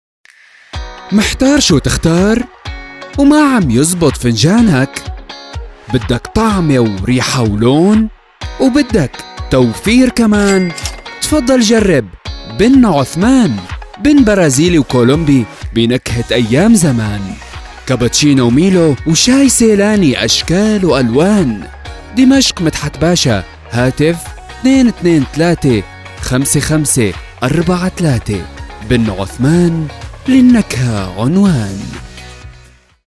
诙谐轻松